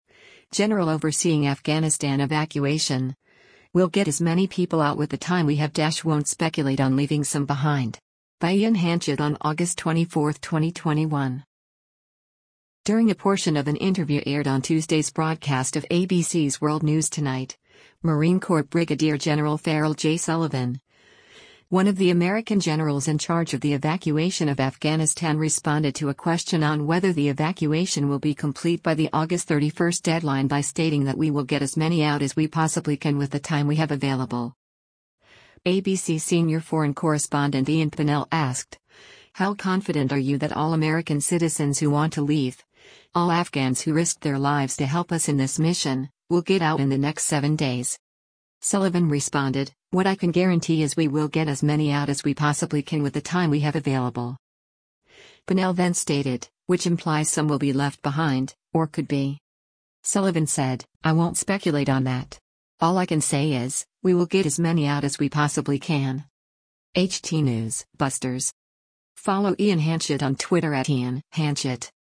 During a portion of an interview aired on Tuesday’s broadcast of ABC’s “World News Tonight,” Marine Corps Brig. Gen. Farrell J. Sullivan, one of the American generals in charge of the evacuation of Afghanistan responded to a question on whether the evacuation will be complete by the August 31 deadline by stating that “we will get as many out as we possibly can with the time we have available.”